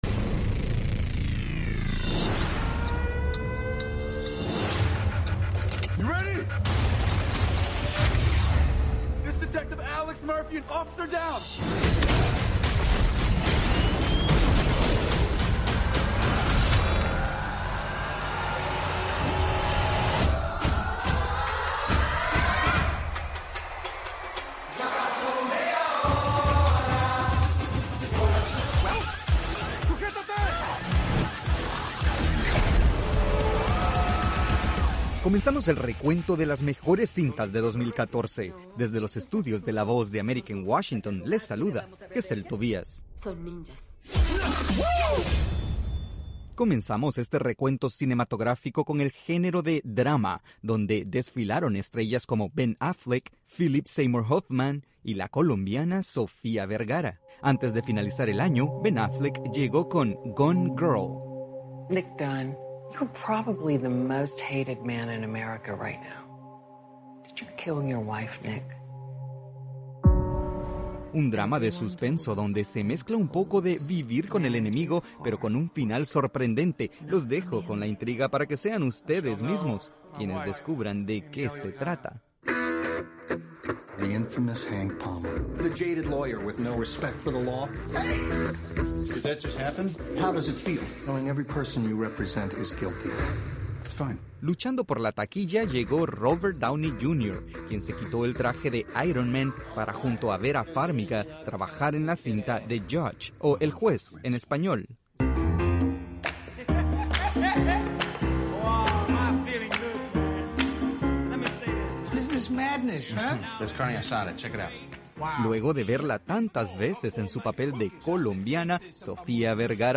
Buenos días América es un programa informativo diario de media hora dirigido a nuestra audiencia en América Latina. El programa se transmite de lunes a viernes de 8:30 a.m. a 9:00 a.m. [hora de Washington].